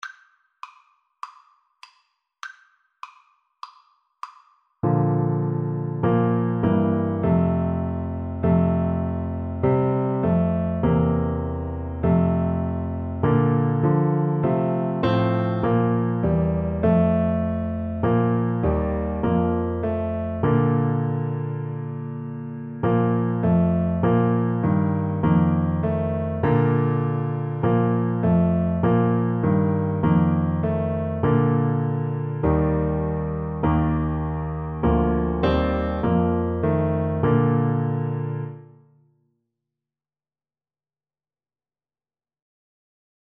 Bb major (Sounding Pitch) (View more Bb major Music for Tuba )
4/4 (View more 4/4 Music)
Classical (View more Classical Tuba Music)